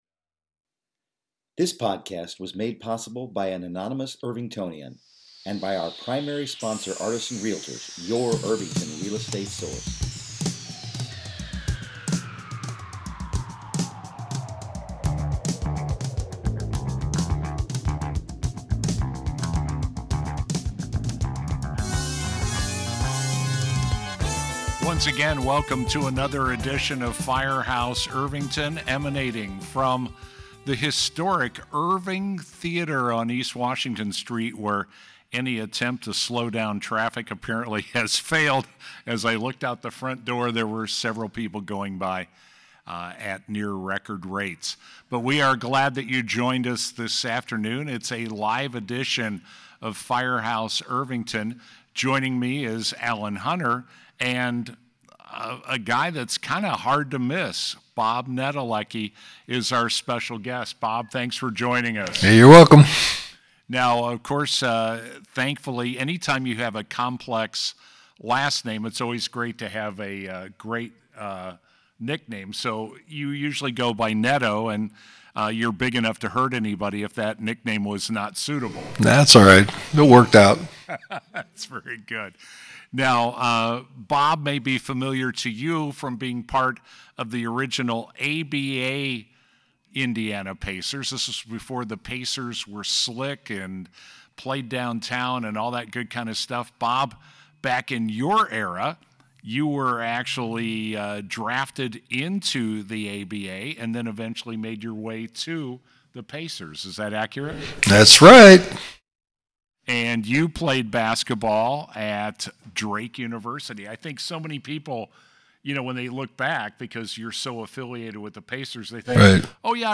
Interview with ABA Pacers superstar Bob Netolicky